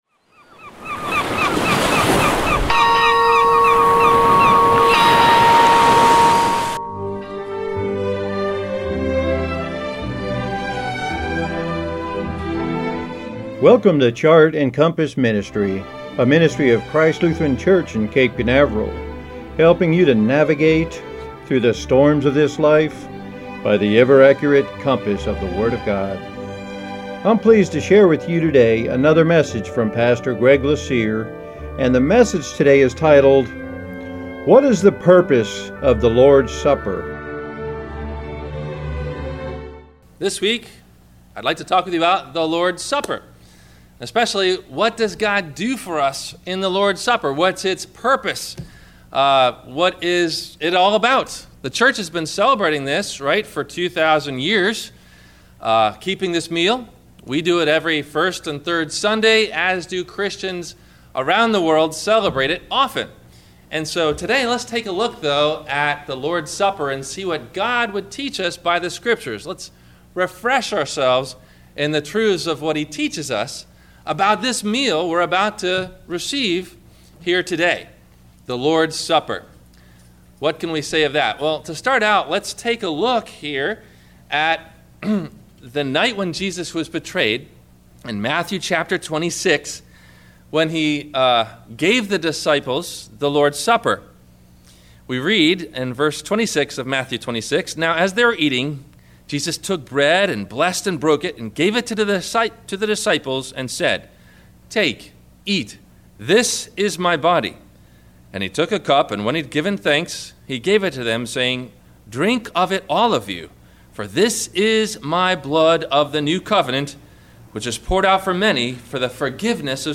What is the Purpose of the Lord’s Supper? – WMIE Radio Sermon – February 08 2016